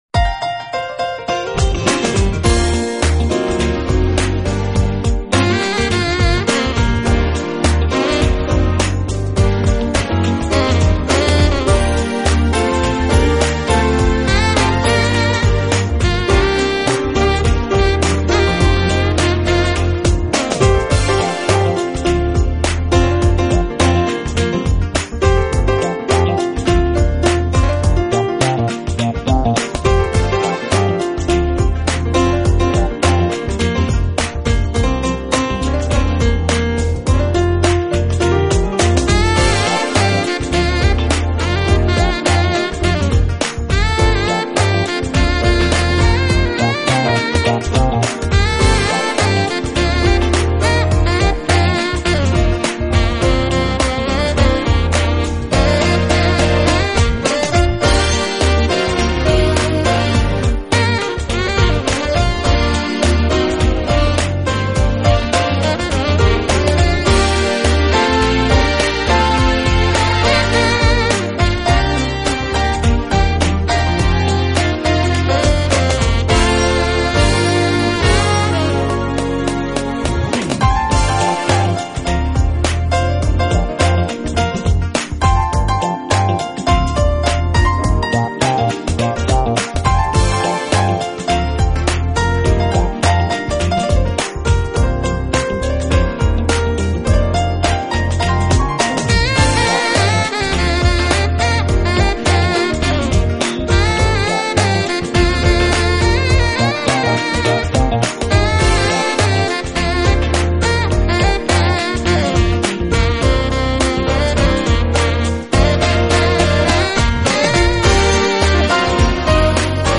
音乐类型: Smooth Jazz / Contemporary Jazz
所走的是美国主流器乐演奏路线，当然也是非常主流化的Smooth Jazz。